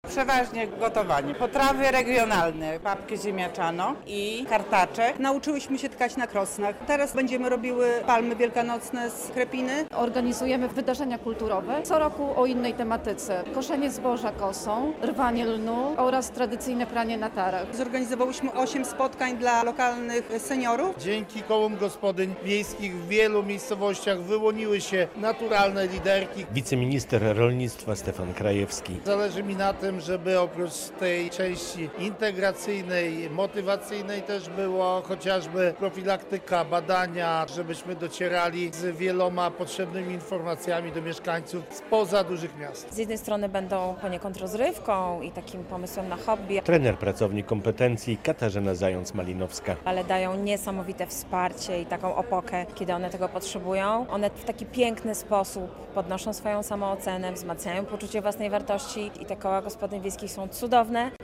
Konferencja Kół Gospodyń Wiejskich w Marianowie - relacja